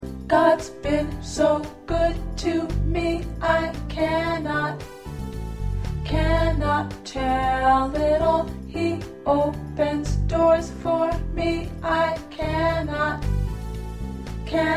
3 parts — Soprano, Alto, Tenor.